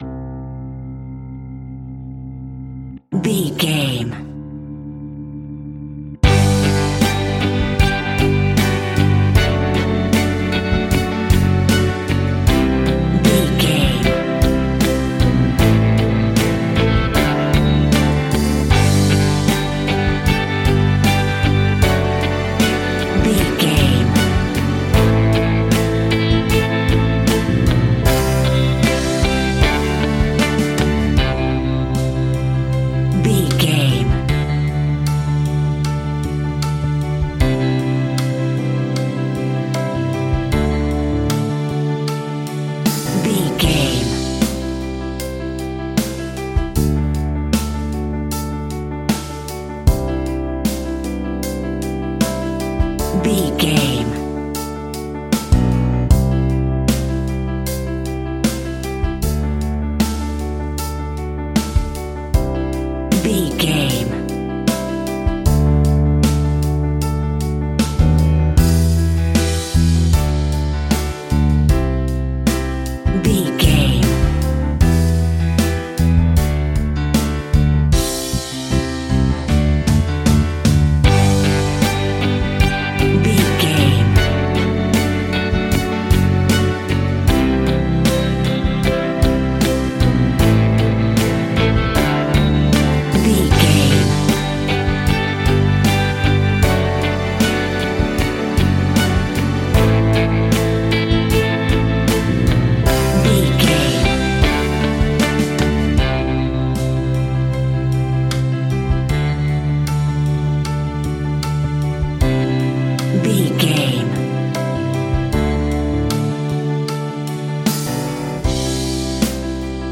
Pop Rock Anthem Full Mix.
Aeolian/Minor
pop rock instrumentals
happy
upbeat
bouncy
drums
bass guitar
electric guitar
keyboards
hammond organ
acoustic guitar
percussion